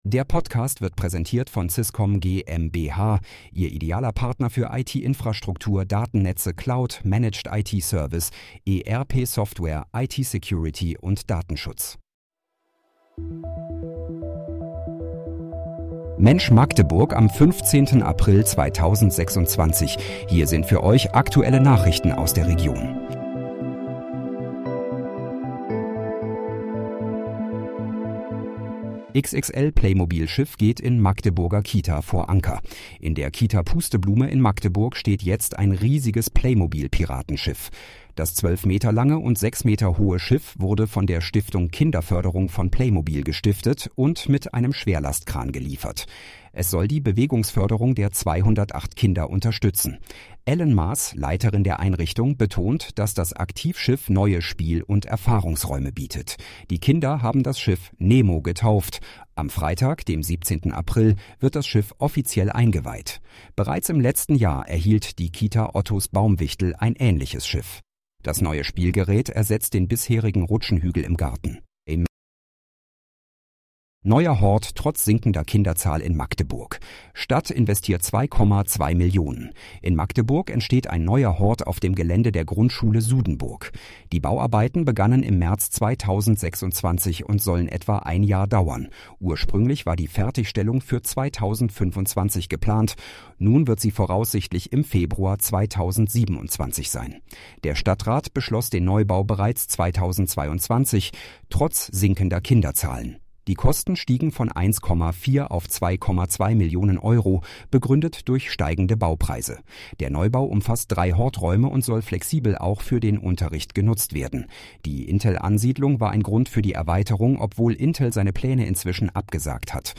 Mensch, Magdeburg: Aktuelle Nachrichten vom 15.04.2026, erstellt mit KI-Unterstützung